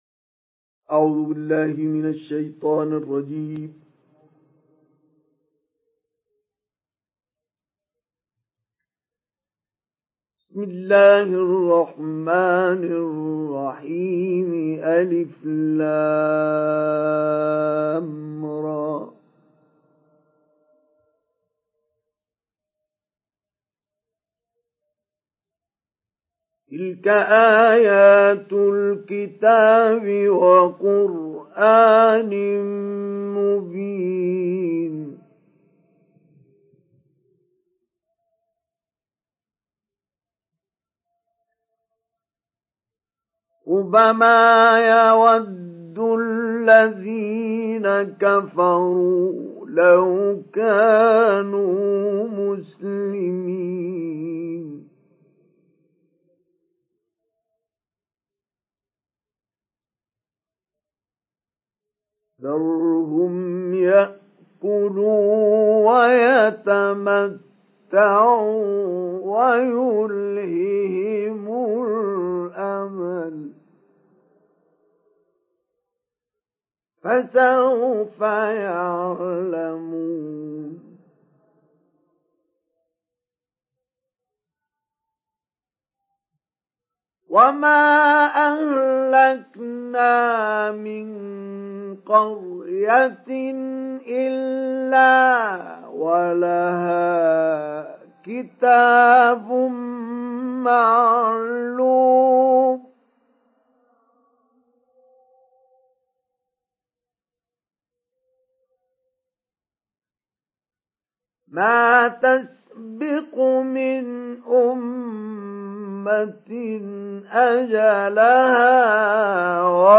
سُورَةُ الحِجۡرِ بصوت الشيخ مصطفى اسماعيل